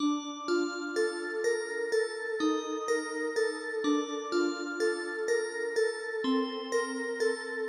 标签： 125 bpm Trap Loops Bells Loops 1.29 MB wav Key : A